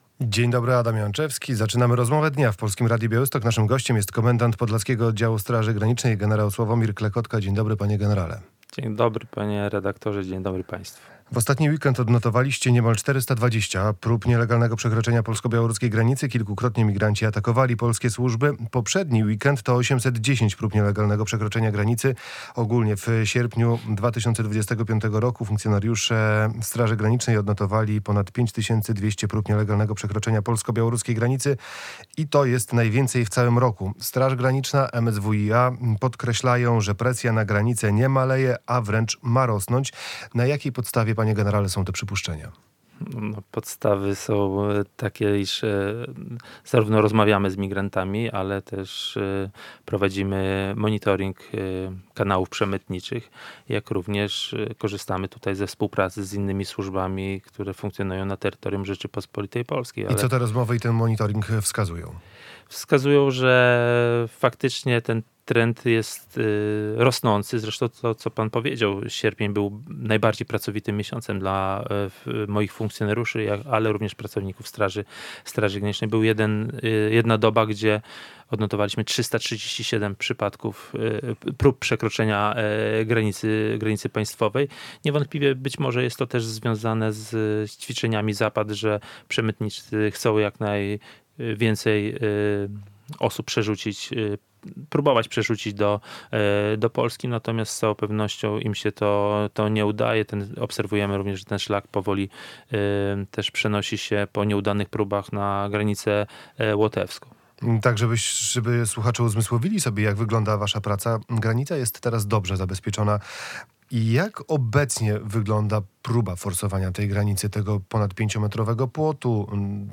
Radio Białystok | Gość | gen. Sławomir Klekotka - komendant Podlaskiego Oddziału Straży Granicznej